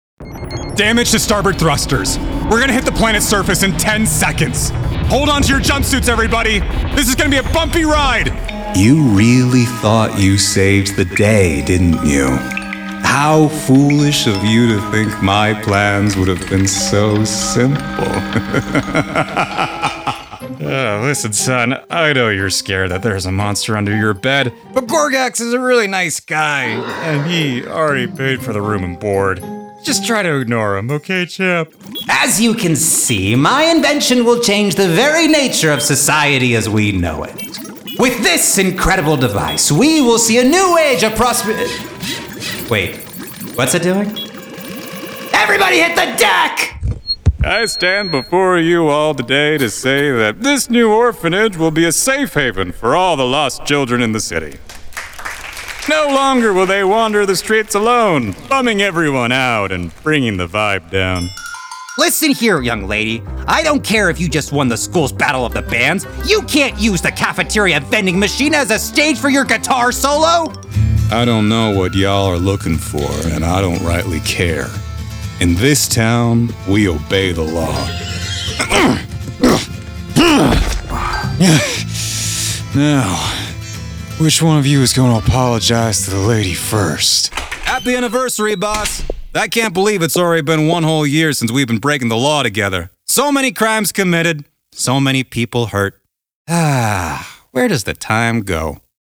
Voiceover Demos
Recorded in my home studio in Tucson, AZ with:
Microphone- Shure SM7B
I use a Stanislavski-based approach to uncover the humanity in each character I voice and deliver an honest performance from the heart.